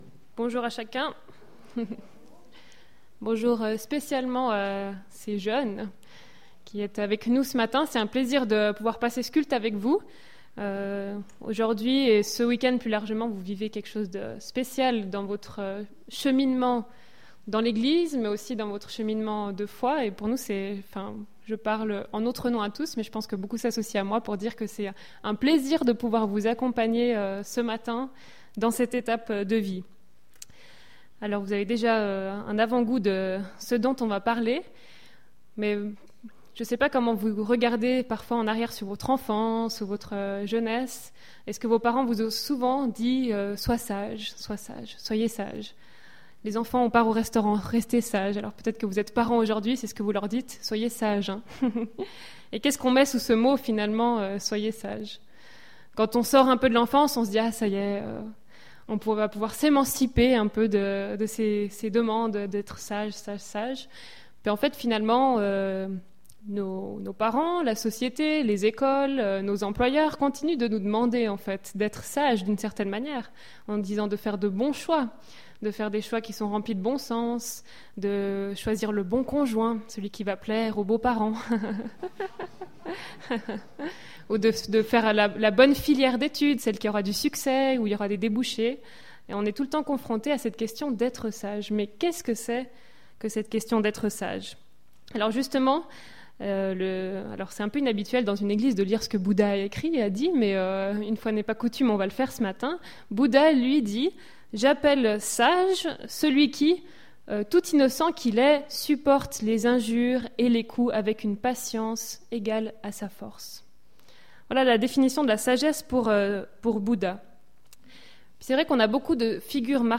Culte du 19 juin 2016